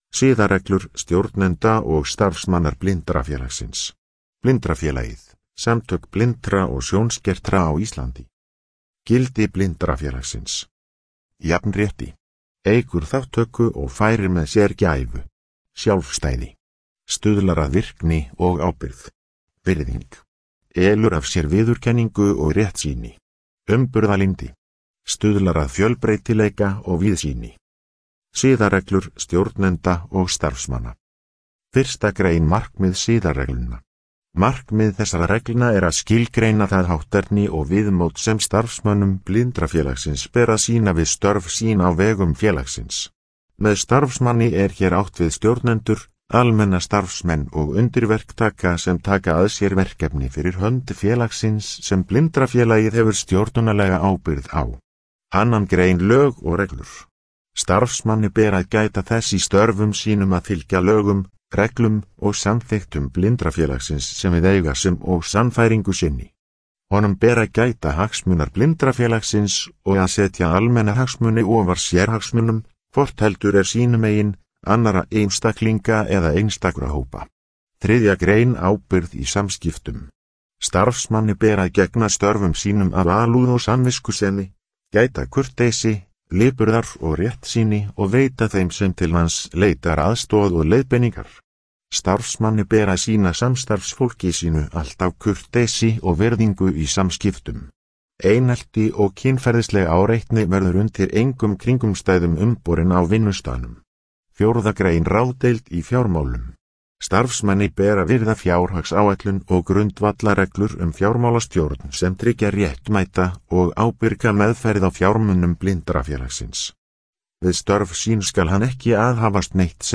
Upplesið